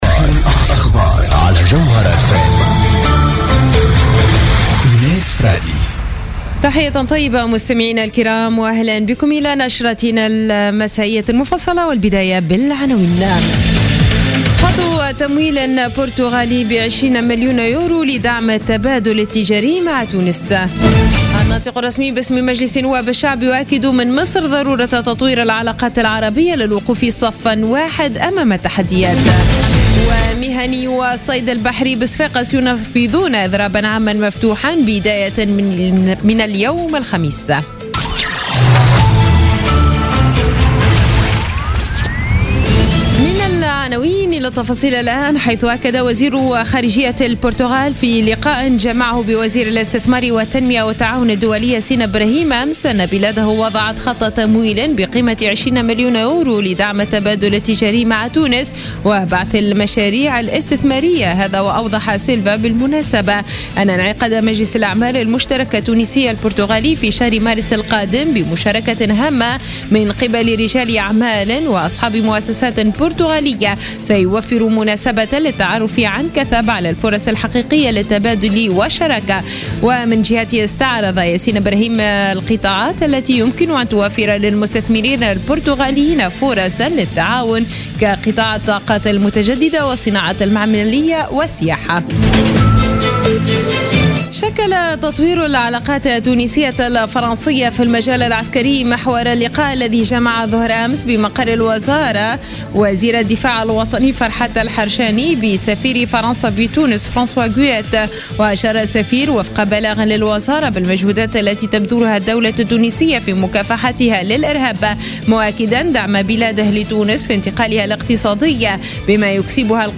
Journal Info 00h00 du jeudi 25 février 2016